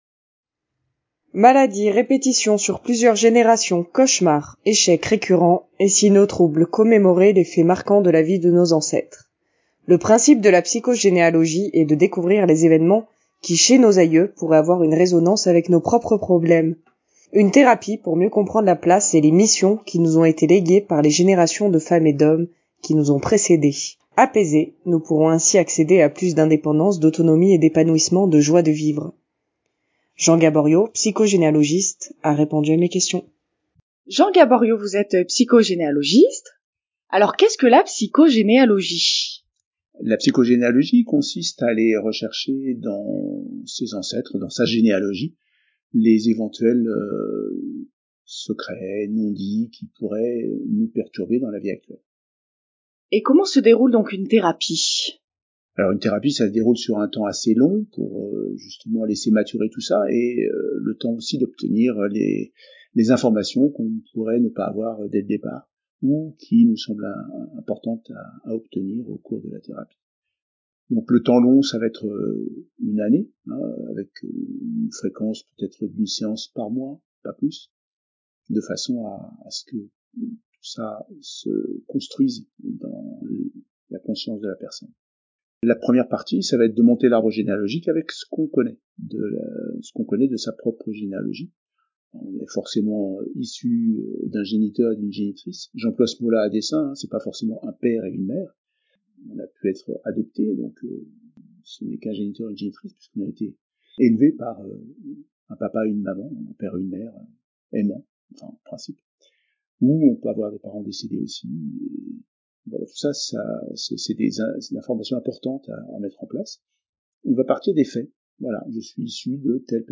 psychogénealogiste, répond aux questions